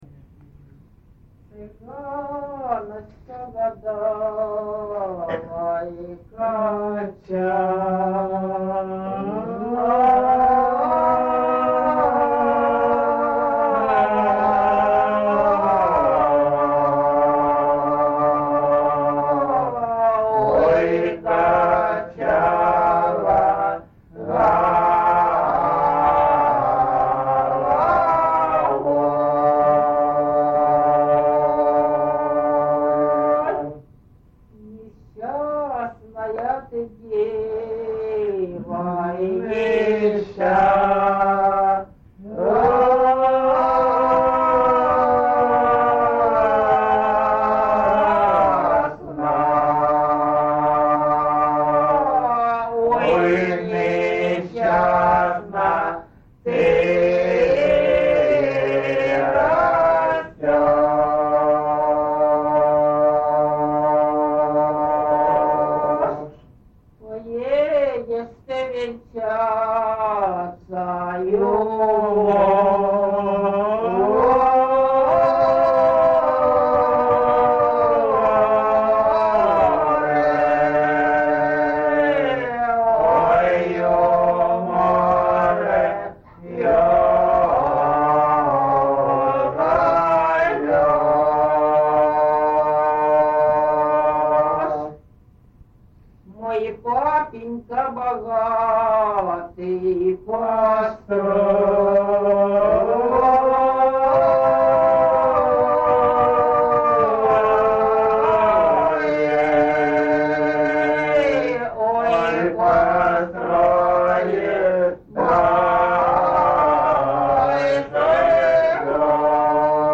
ЖанрПісні з особистого та родинного життя, Балади
Місце записус. Маринівка, Шахтарський (Горлівський) район, Донецька обл., Україна, Слобожанщина